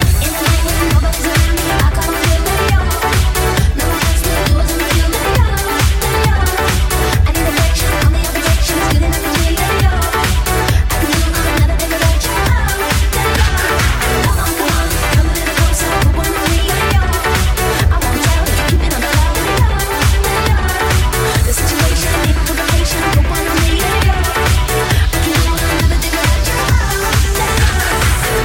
Genere: dance, house, electro, club, remix, 2008